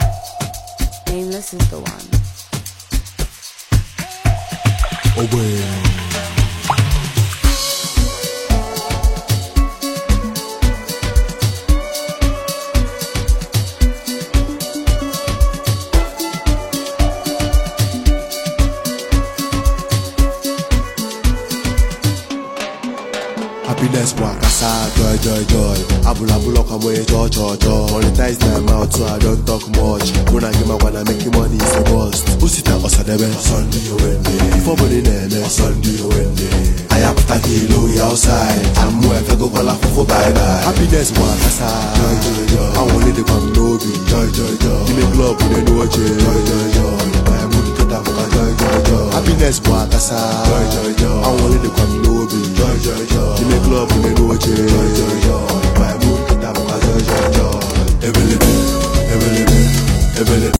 Nigerian rapper and songwriter